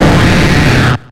Cri de Léviator dans Pokémon X et Y.